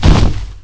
bomb_explode.wav